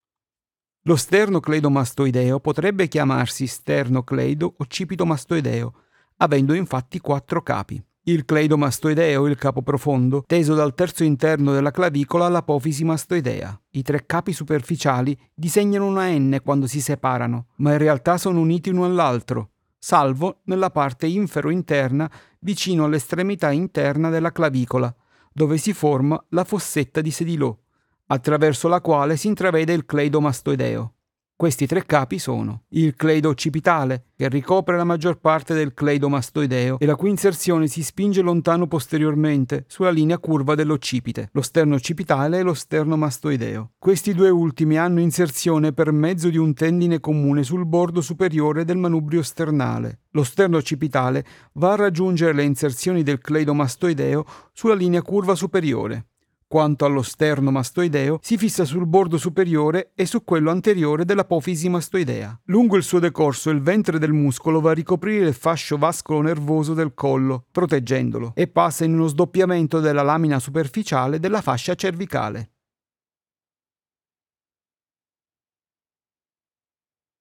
Italian speaker and voice artist, warm voice, young, middle, old, character voices, medical narration, e-learning, ads, commercial, audiobooks, IVR and phone system
Sprechprobe: eLearning (Muttersprache):
I have a warm voice and I can do a variety of voice delivery.
Usually I work with a CAD e100s condenser microphone, a Solid State Logic 2 audio interface but I have a tube amplifier if that kind of sound is needed.